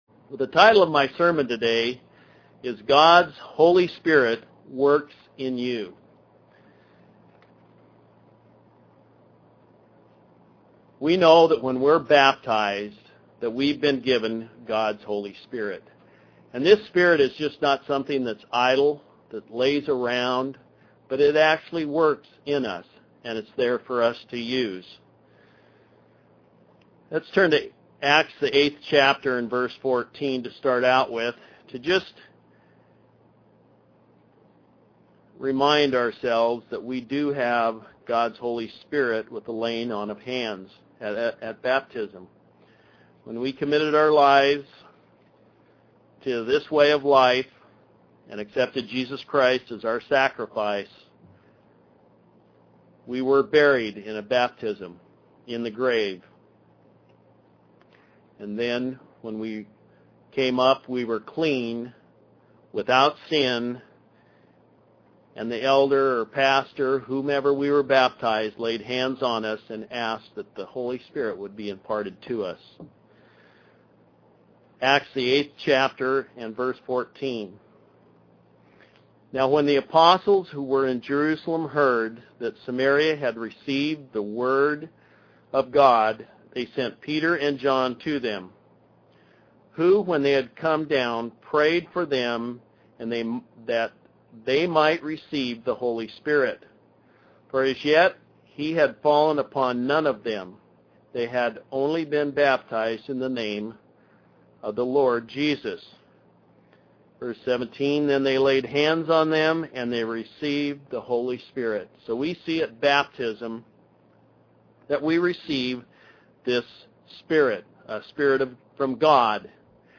This sermon explains what the Holy Spirit does in the lives of Christians. It also explains how to increase the power of the Holy Spirit and, conversely, how it can be quenched.